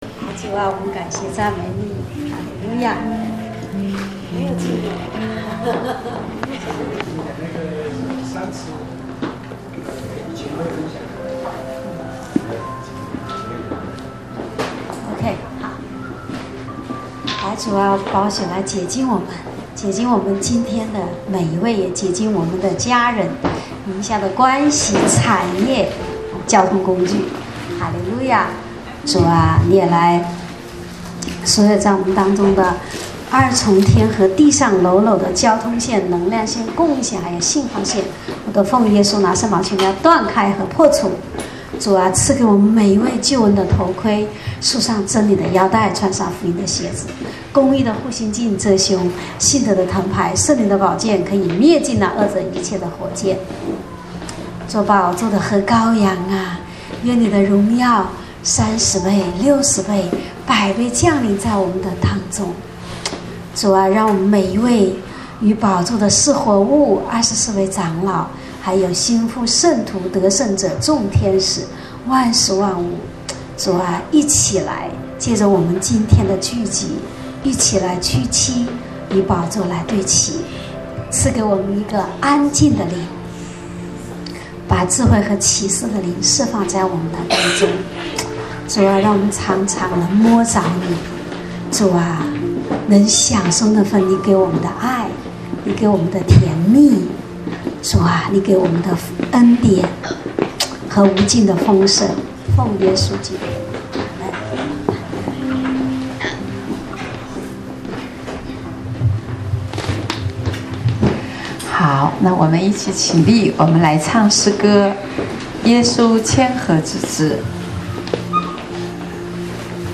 正在播放：--2014年11月30日主日恩膏聚会（2014-11-30）